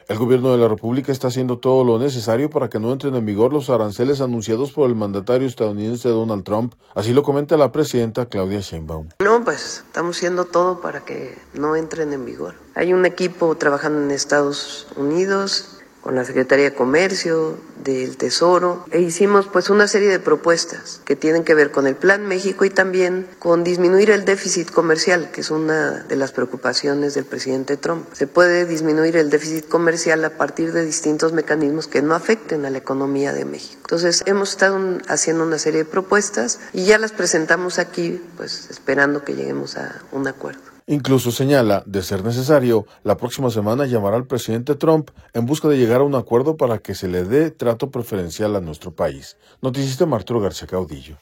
El Gobierno de la República está haciendo todo lo necesario para que no entren en vigor los aranceles anunciados por el mandatario estadounidense Donald Trump, así lo comenta la presidenta Claudia Sheinbaum.